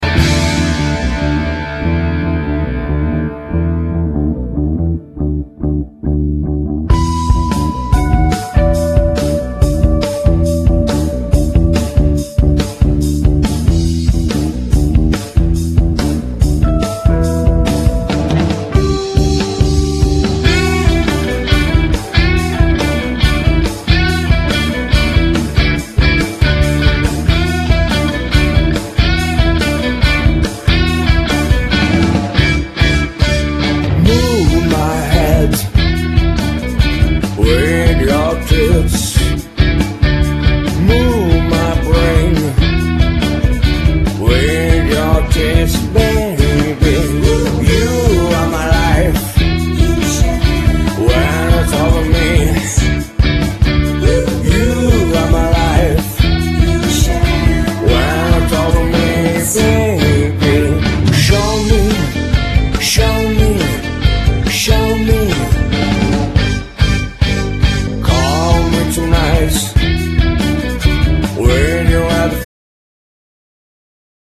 chitarra, basso, batteria e voce, non serve nient'altro.
Genere : Pop